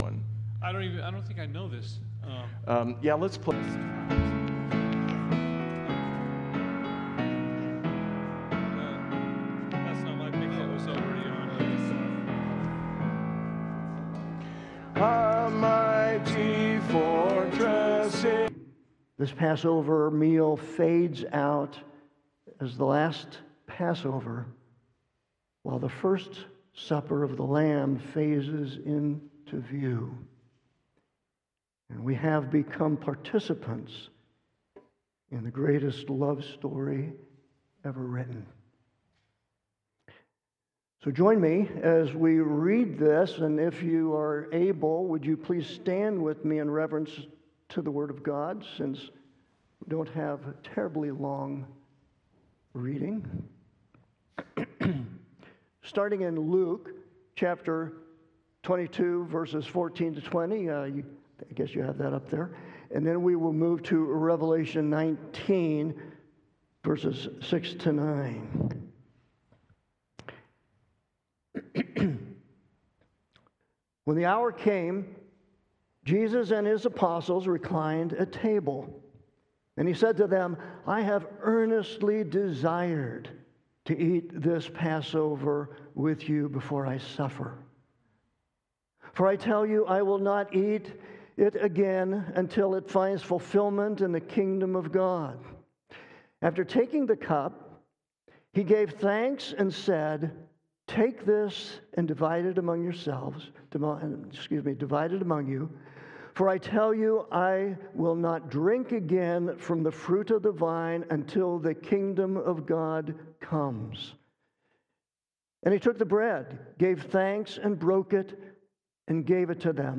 Sermons at New Life Christian Reformed Church